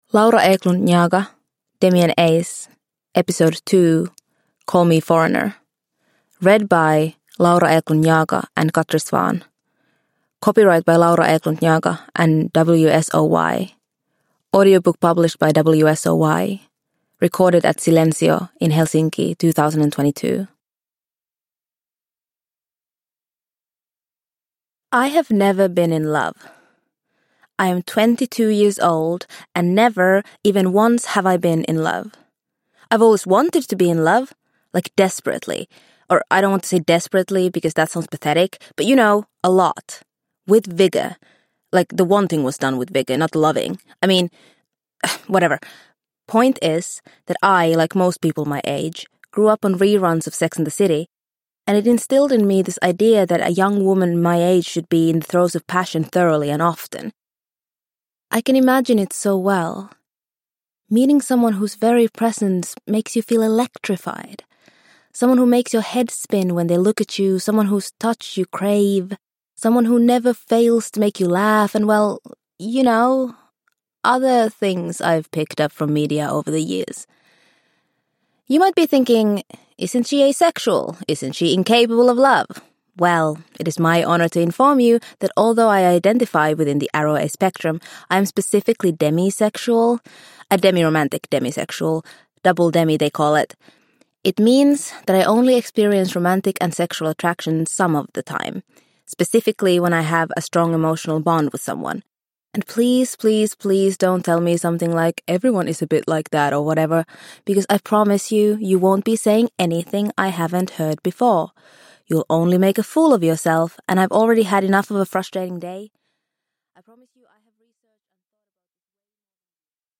A funny and moving audio series about how weird love is.